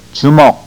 To hear proper pronunciation, click one of the links below
Choo Muk - Fist